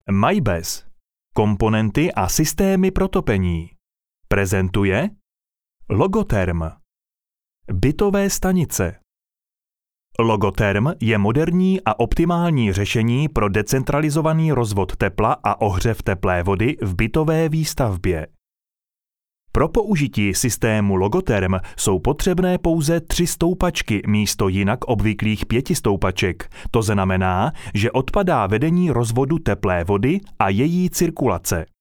Sprecher tschechisch für TV / Rundfunk / Industrie Werbung.
Sprechprobe: eLearning (Muttersprache):
Professionell voice over artist from Czech.